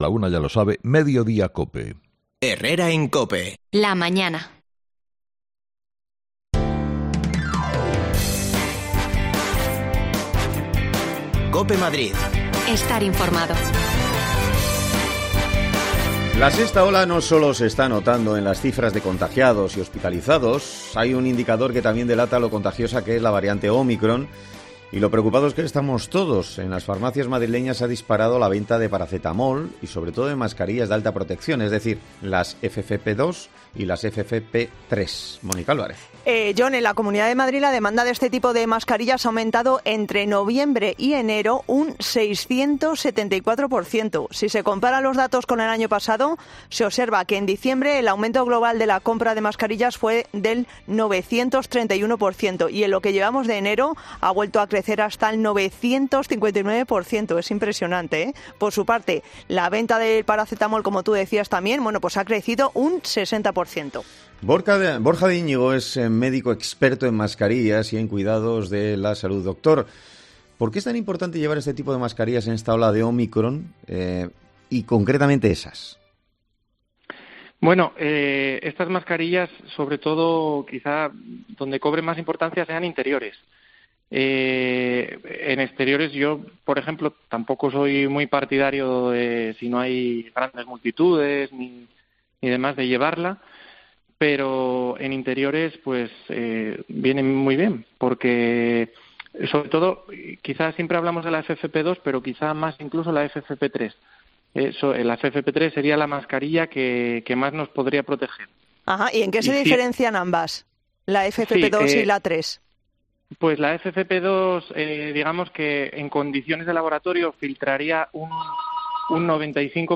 Hablamos con un experto para conocer cuáles son las mejores en cada situación
Las desconexiones locales de Madrid son espacios de 10 minutos de duración que se emiten en COPE , de lunes a viernes.